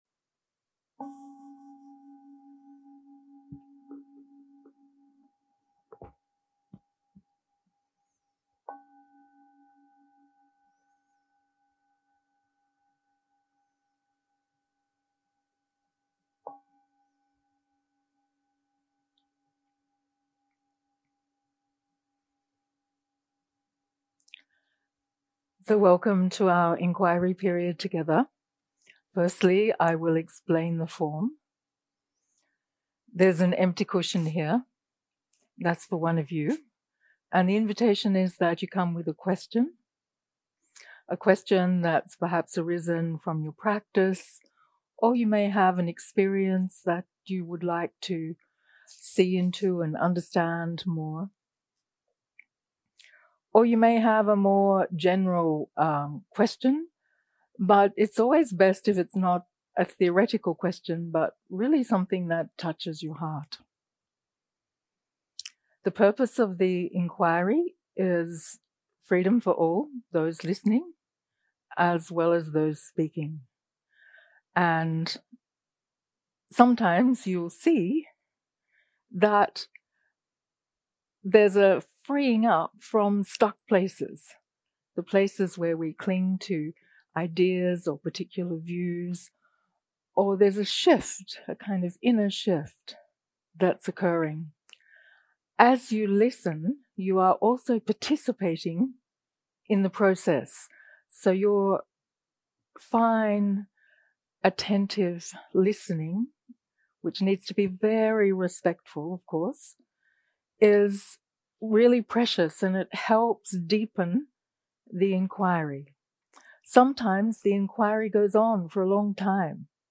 Dharma type: Inquiry